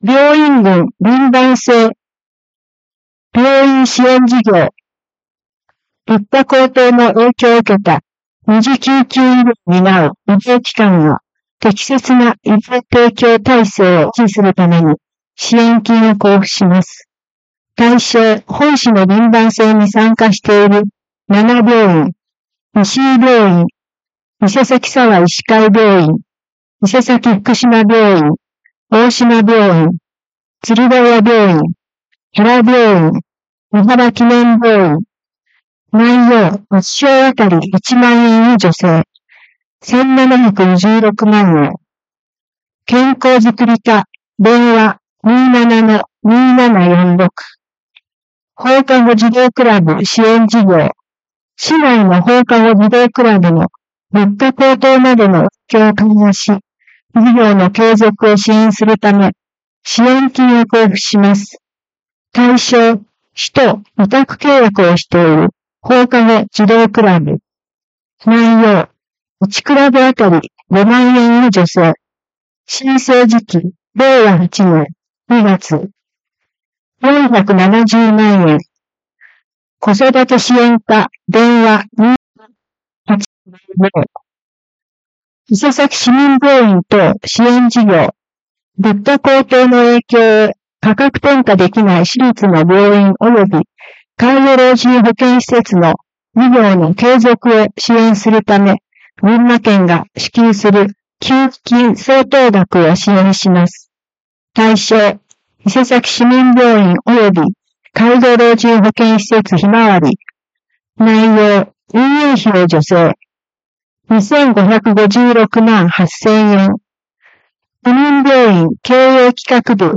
声の広報は目の不自由な人などのために、「広報いせさき」を読み上げたものです。
朗読
伊勢崎朗読奉仕会